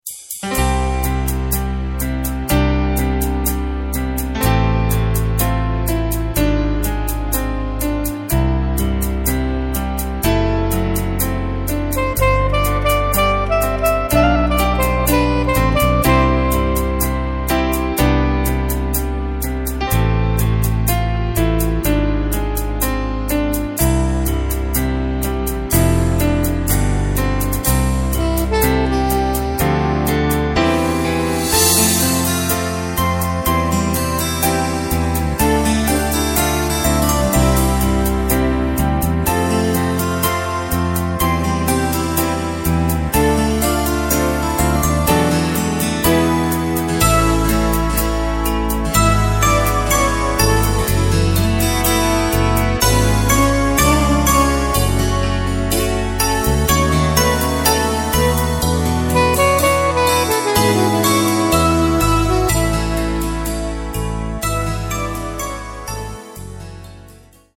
Takt:          4/4
Tempo:         119.00
Tonart:            C
Austropop aus dem Jahr 1990!